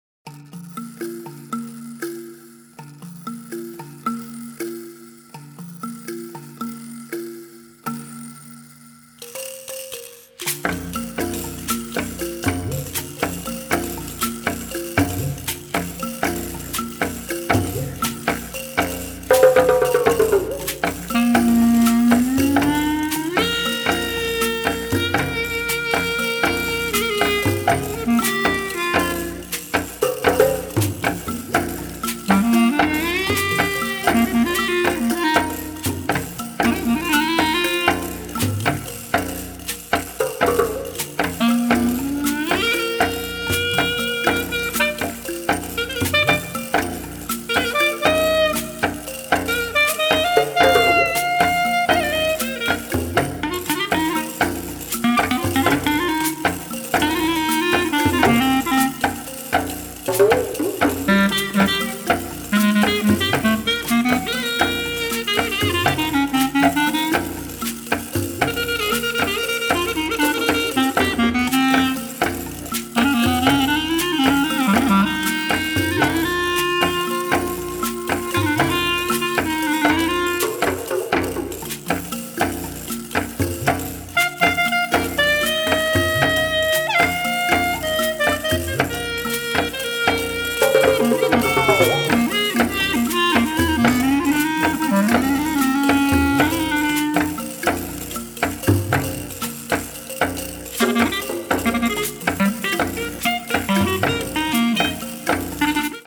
エキゾチックかつスピリチュアルな魅力に満ちていて◎！ワールド・ミュージック経由のジャズといった趣が素晴らしいですよ！